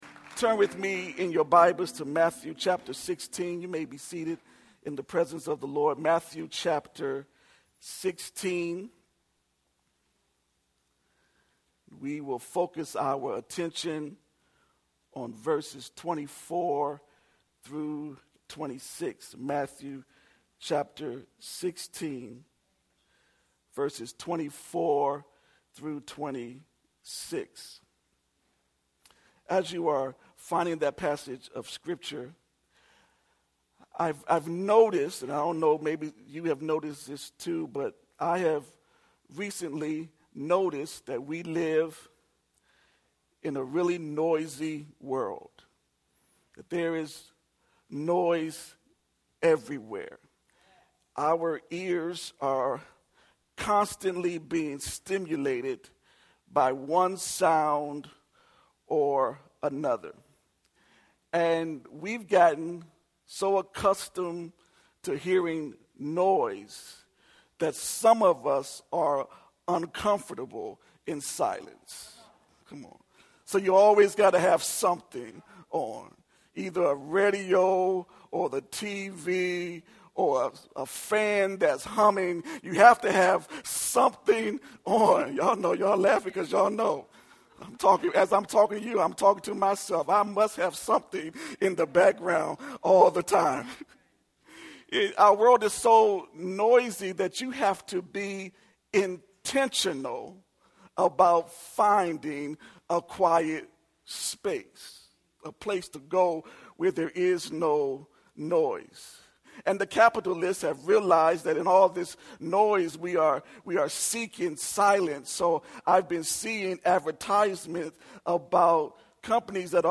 4-7-19-Sunday-Sermon-Distracted-.mp3